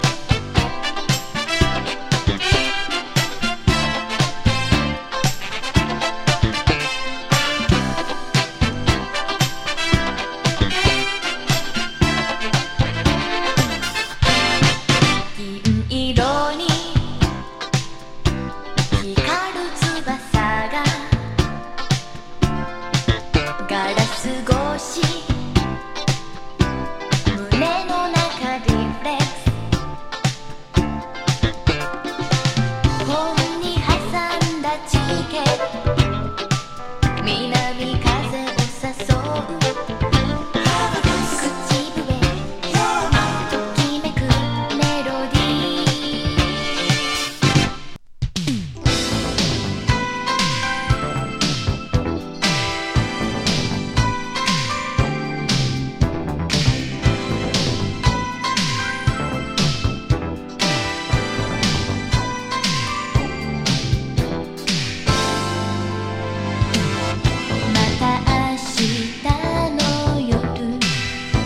スムース・モダン・ファンカー